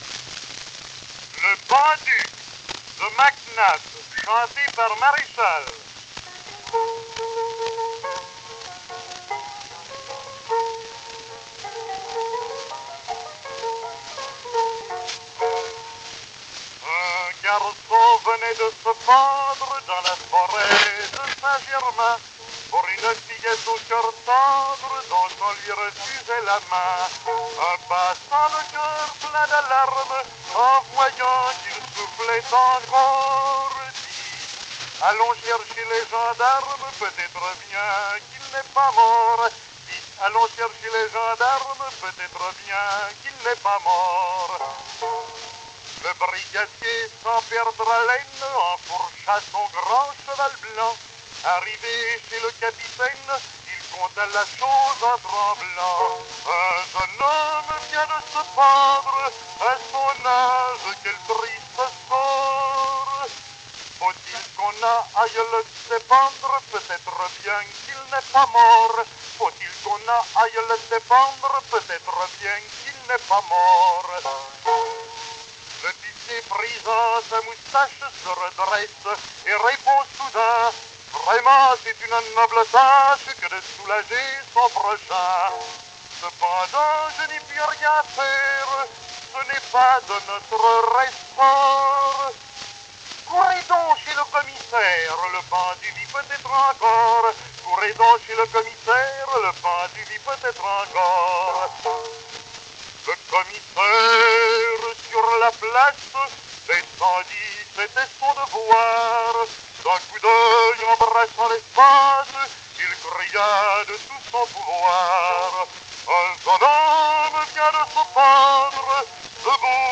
mit Klavierbegleitung